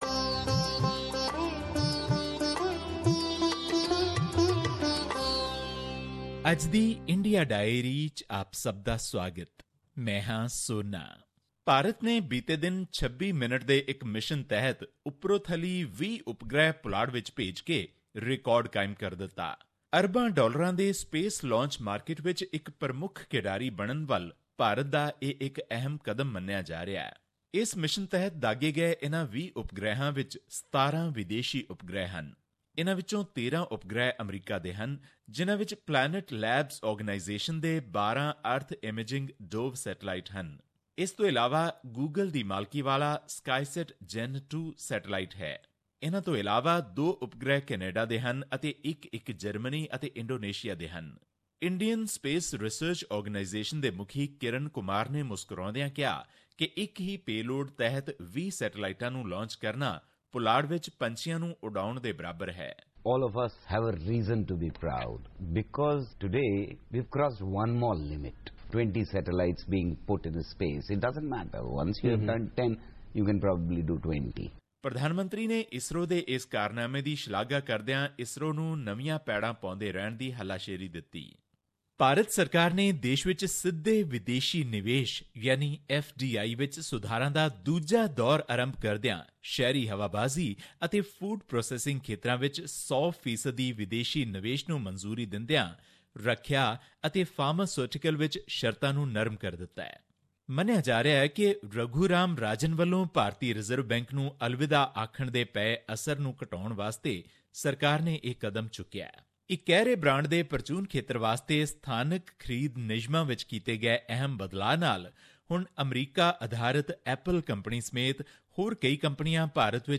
His report was presented on SBS Punjabi program on Thursday, June 23, 2016, which touched upon issues of Punjabi and national significance in India. Here's the podcast in case you missed hearing it on the radio.